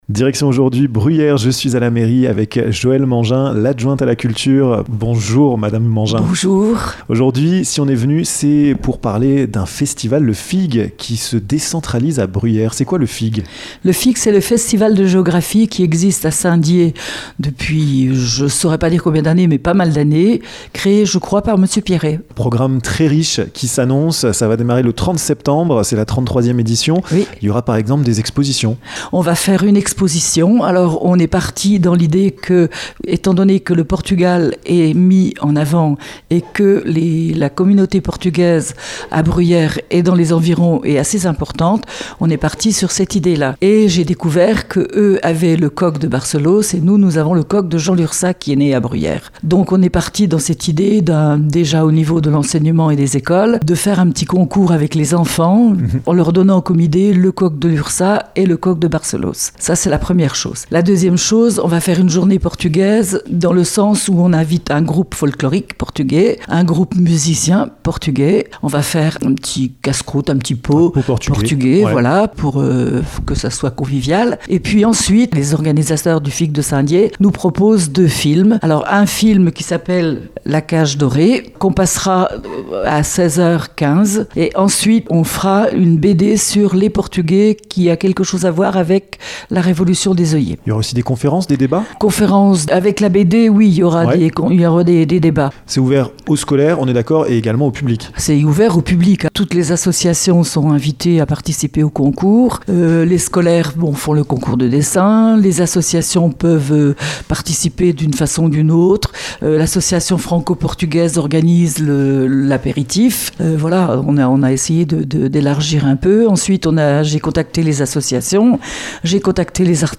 Le Festival International de Géographie prendra place à Bruyères à partir de ce samedi 1er octobre. Le pays invité pour cette nouvelle édition est le Portugal. Joëlle Mangin, adjointe à la culture de la ville de Bruyères, vous donne le programme!